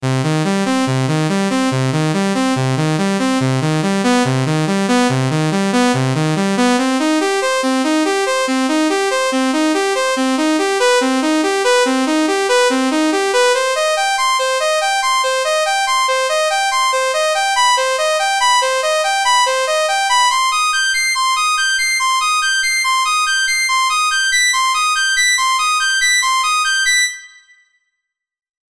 They are clear and bright and sometimes angry sounding depending on the context.
Sawtooth wave
Sawtooth_-3dB.mp3